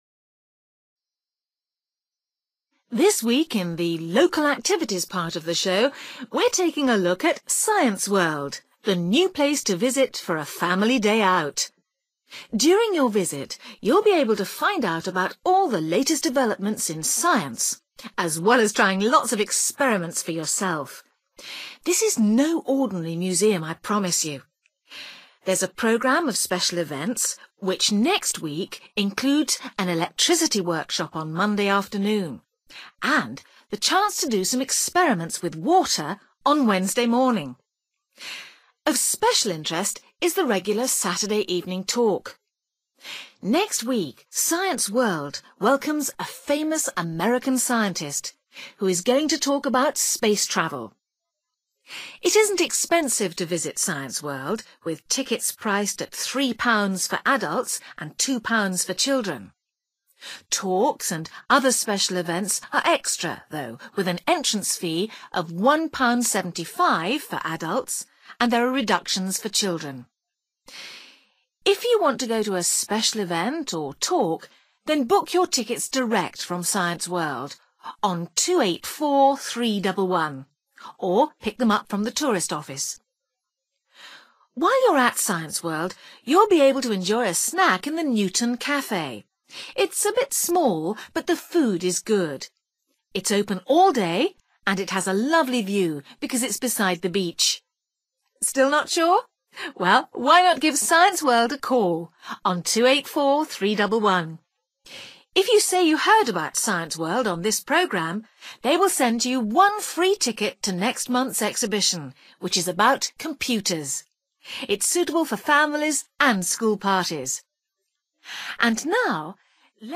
You will hear a radio announcer talking about activities at a museum called Science World.